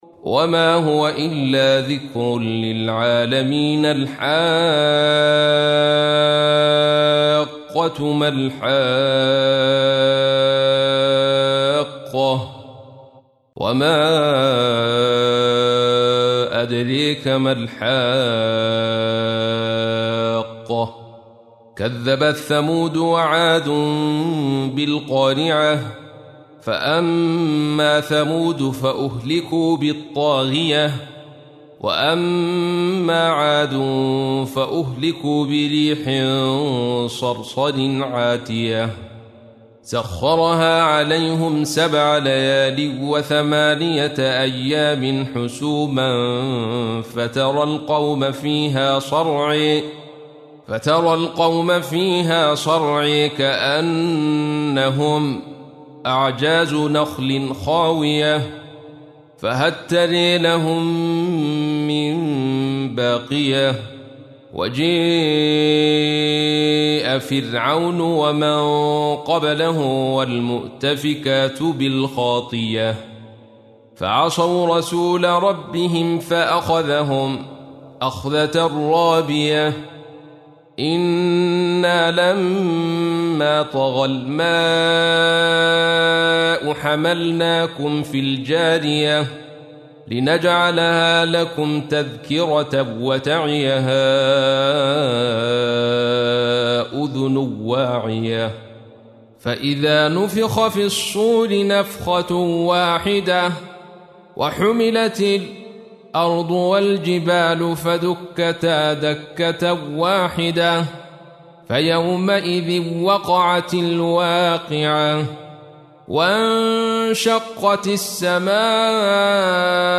تحميل : 69. سورة الحاقة / القارئ عبد الرشيد صوفي / القرآن الكريم / موقع يا حسين